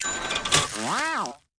Block Toaster Sound Effect
Download a high-quality block toaster sound effect.
block-toaster.mp3